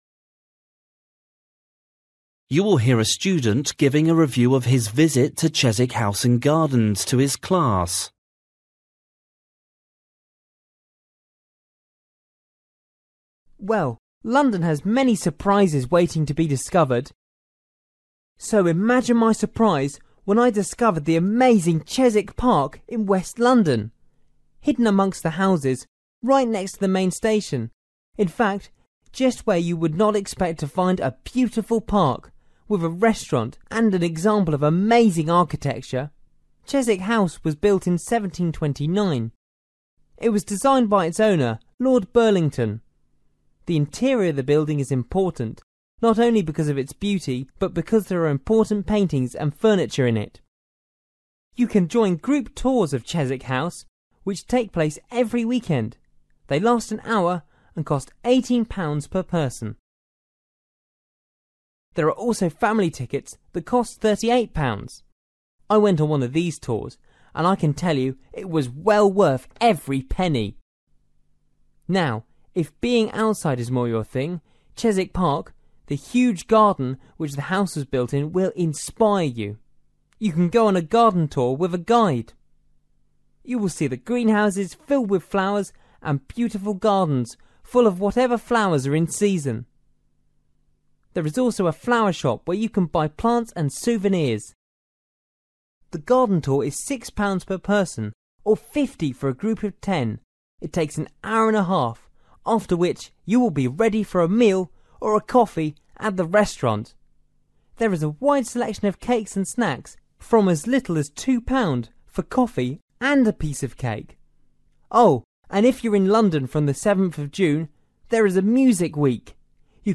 You will hear a student giving a review of his visit to Chiswick House and Gardens to his class.